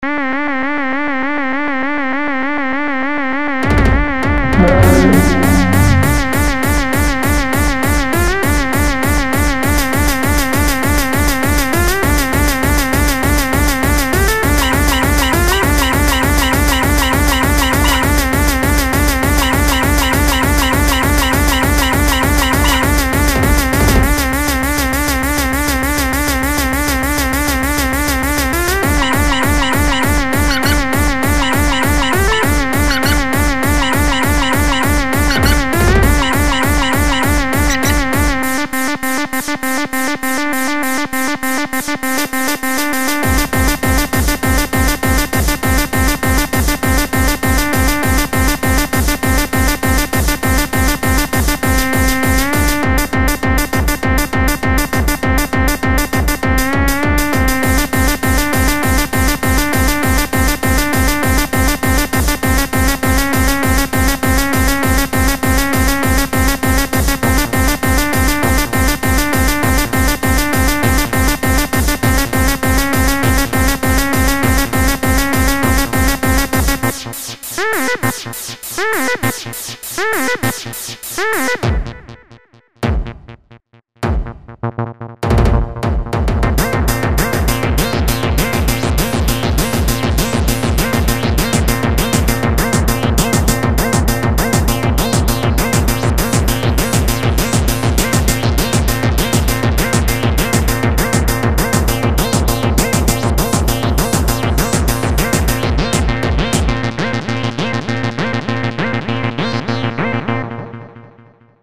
le 6ème titre est un peu plus violent que les autres.